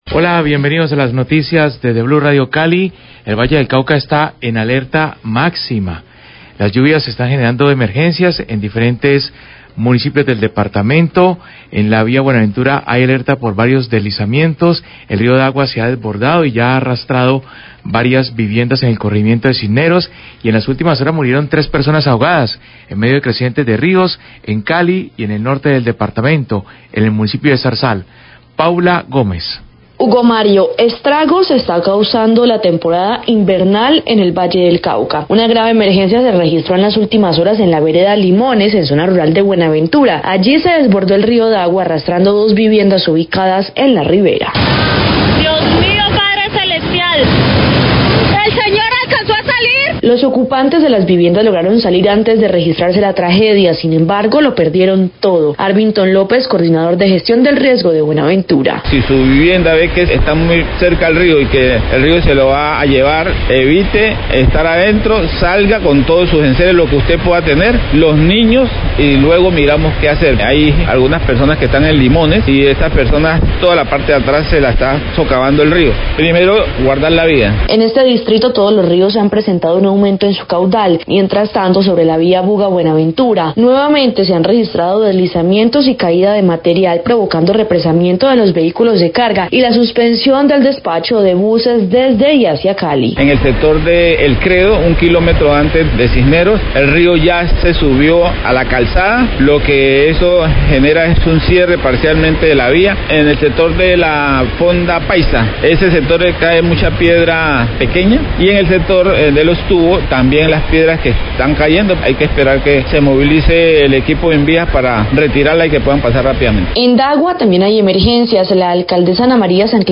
Radio
El Río Dagua presentó una creciente súbita que arrastró varias viviendas. Declaraciones del Srio de Gestión del Riesgo de Buenaventura, Arbinton López, y la Alcaldesa de Dagua, Ana María Sanclemente.